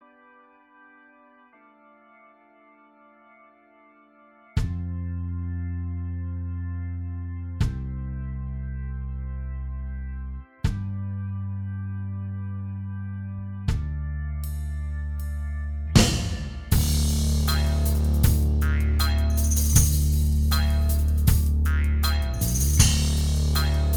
Minus Acoustic Guitars Rock 5:37 Buy £1.50